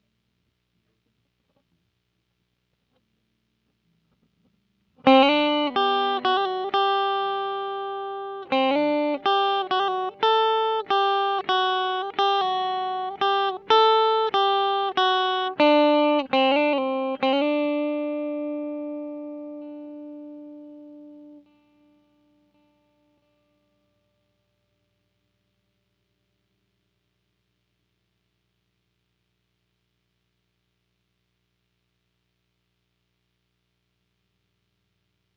Guitar_015.wav